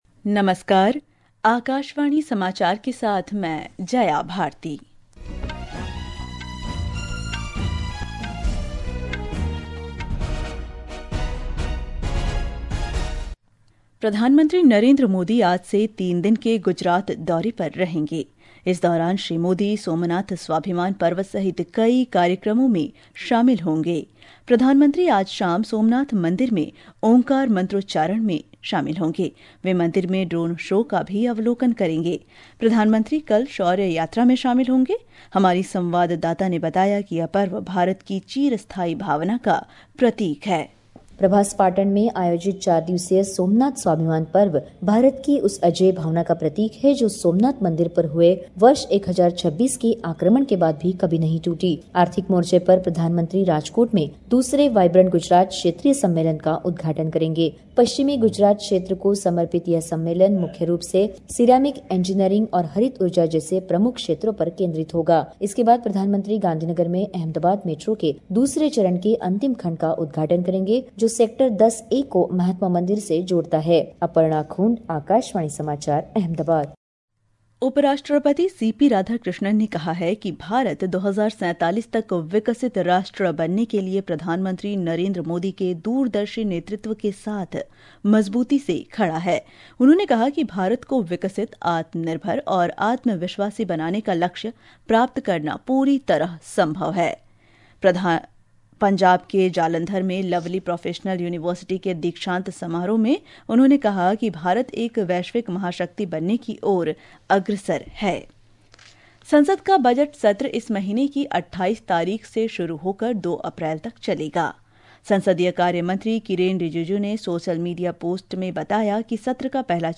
National Bulletins
प्रति घंटा समाचार | Hindi